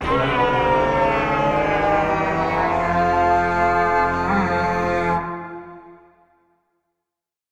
Minecraft Version Minecraft Version snapshot Latest Release | Latest Snapshot snapshot / assets / minecraft / sounds / item / goat_horn / call5.ogg Compare With Compare With Latest Release | Latest Snapshot